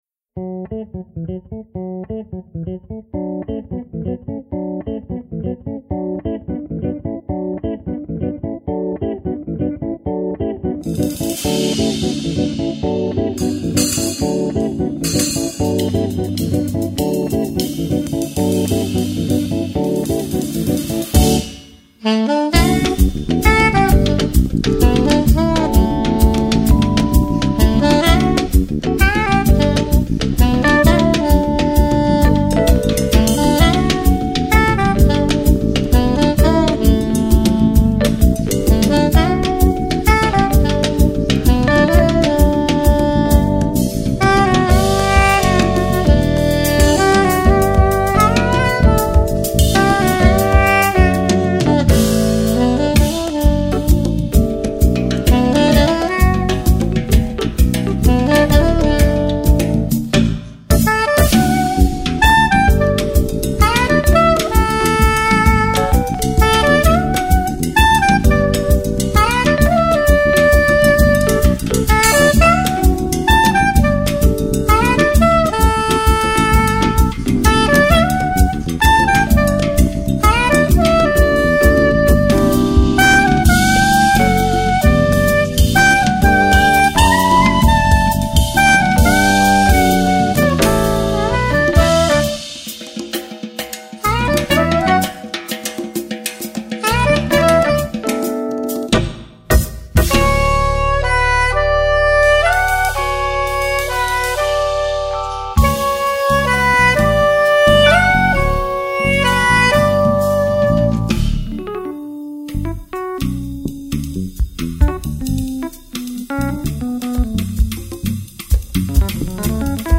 2618   05:36:00   Faixa: 1    Jazz